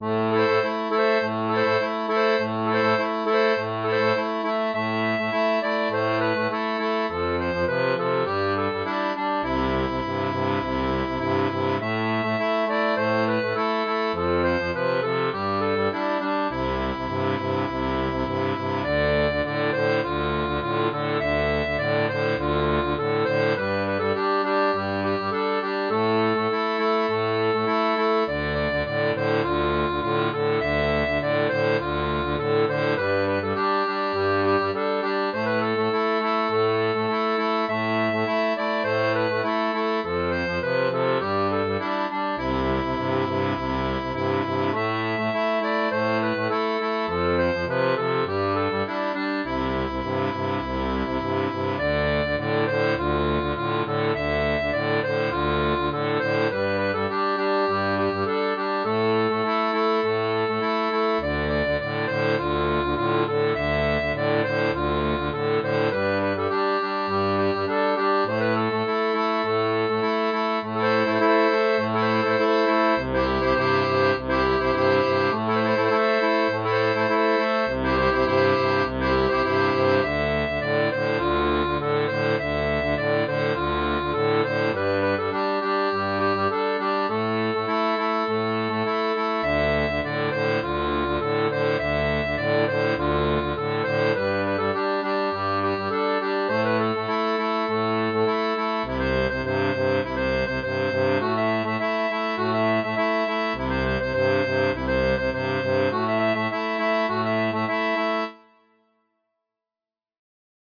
• Un fichier audio basé sur la rythmique originale
Pop-Rock